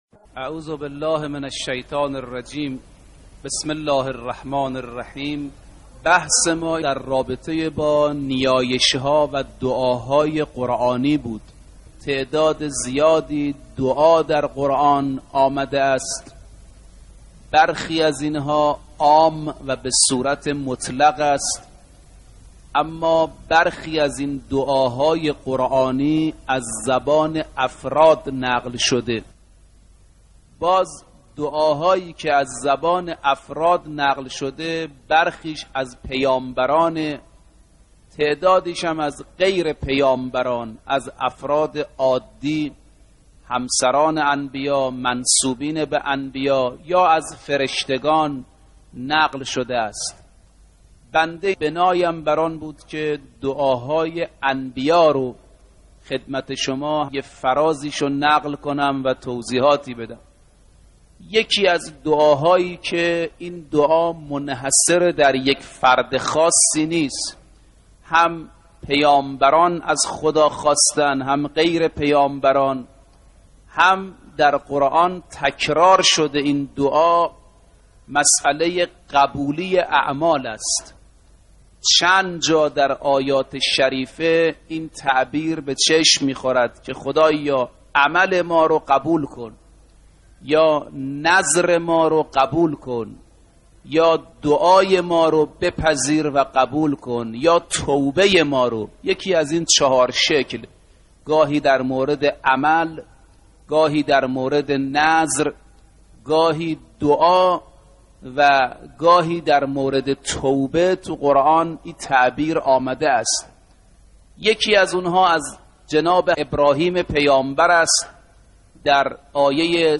سخنرانی حجت الاسلام دکتر ناصر رفیعی با موضوع دعا و نیایش